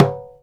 DJEMBE 4A.WAV